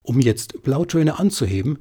Hello, now i have tried most of the tips from you and from the manual in my newest voice-over recording session with the Blue Yeti USB microphone, but the click sounds (with Win 10) remain unchanged (perhaps slightly less frequent, but perhaps just as frequent, certainly with same volume and characteristics).
mono recording
Apart from the click sounds, i get very nice results – very clear voice, no hiss, solid silence in breaks.